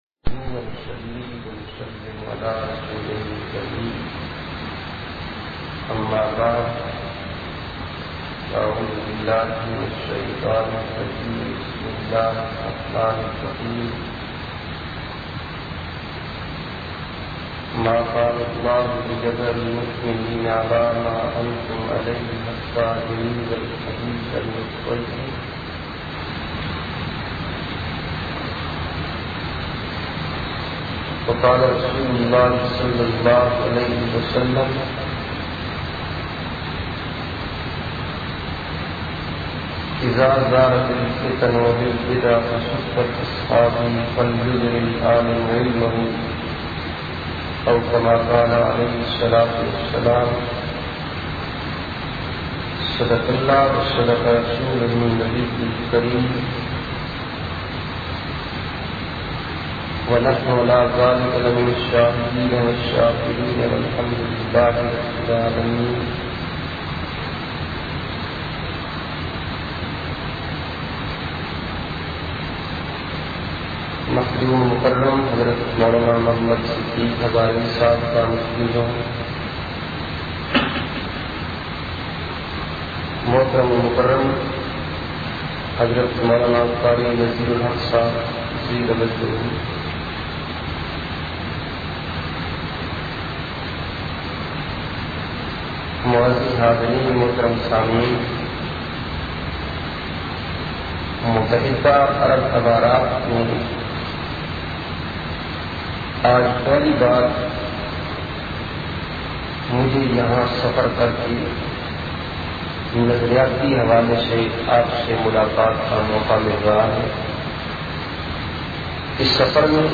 01- Abu-dhabi-khitab.mp3